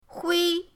hui1.mp3